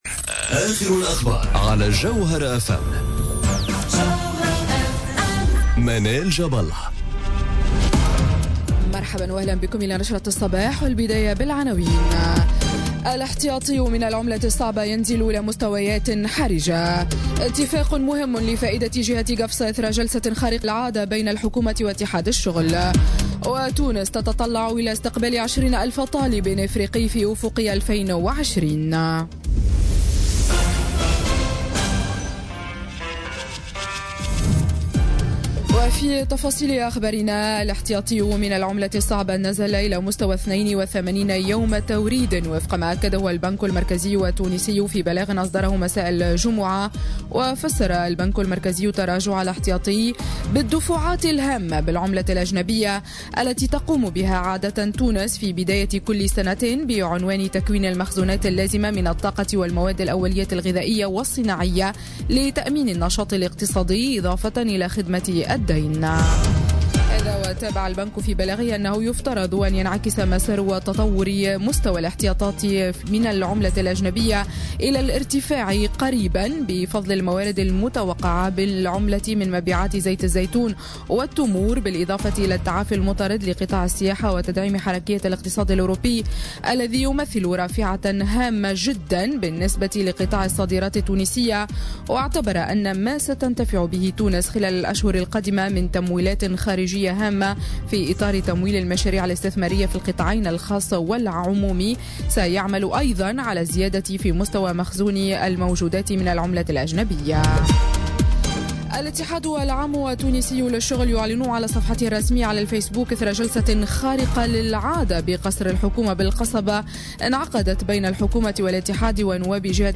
نشرة أخبار السابعة صباحا ليوم السبت 24 فيفري 2018